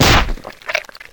Splat Sound 5